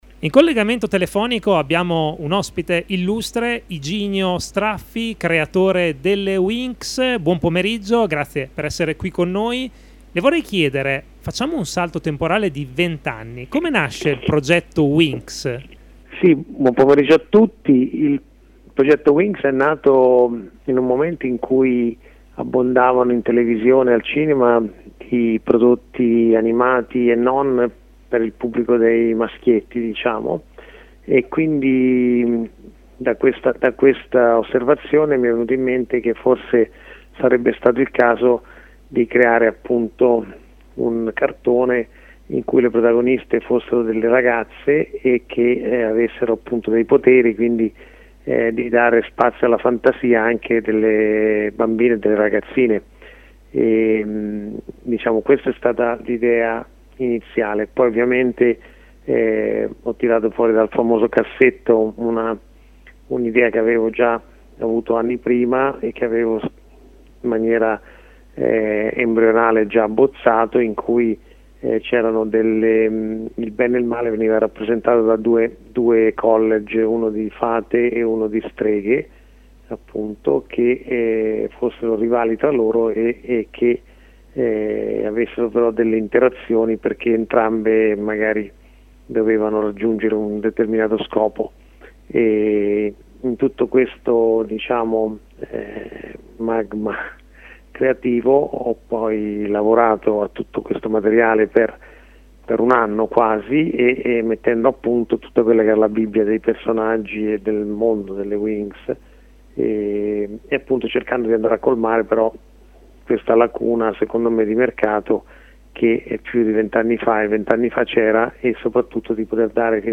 Ascolta l’intervista a Iginio Straffi, presidente e il fondatore di Rainbow ed è noto soprattutto per essere il creatore del cartone animato Winx Club: